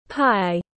Bánh ngọt nướng có nhân tiếng anh gọi là pie, phiên âm tiếng anh đọc là /ˈpaɪ/
Pie /ˈpaɪ/